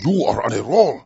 gutterball-3/Gutterball 3/Commentators/Master/zen_uronaroll.wav at 58b02fa2507e2148bfc533fad7df1f1630ef9d9b